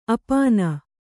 ♪ apāna